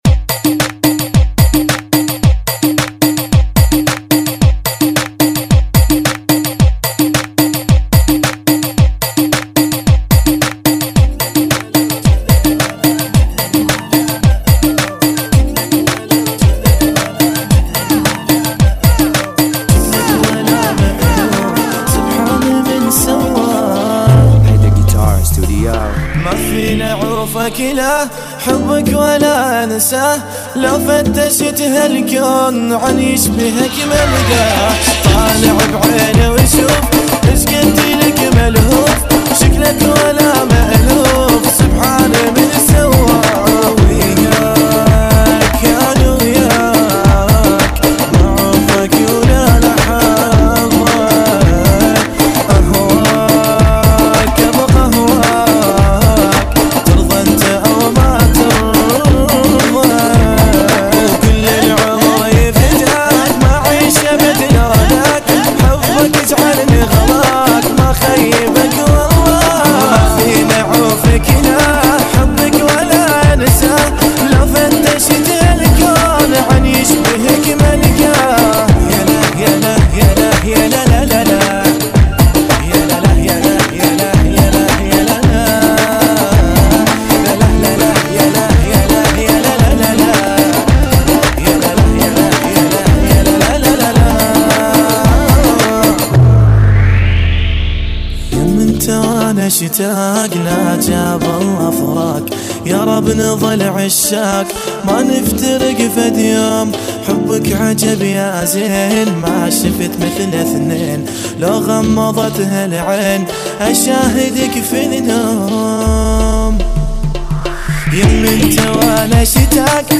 funky